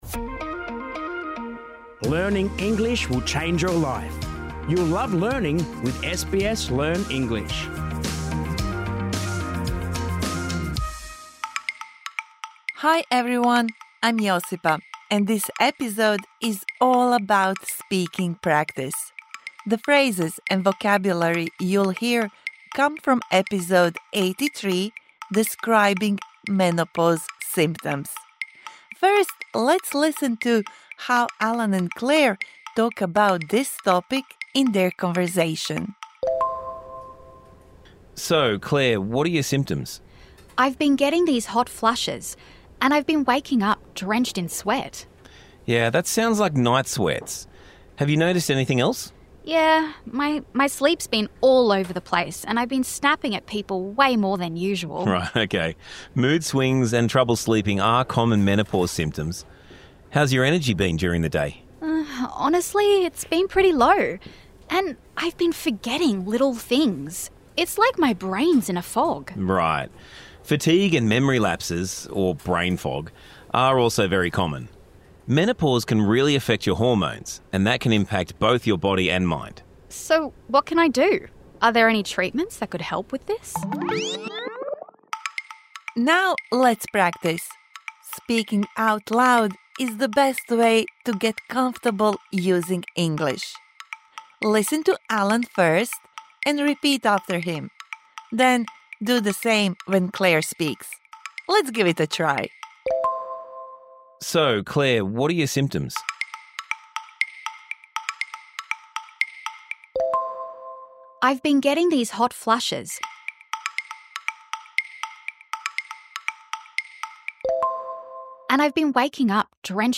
This bonus episode provides interactive speaking practice for the words and phrases you learnt in Episode 83 Describing menopause symptoms.